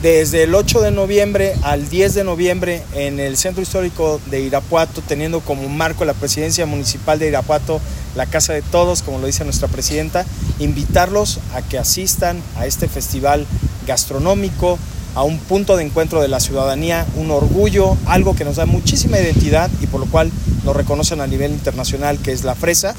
AudioBoletines
Héctor Muñoz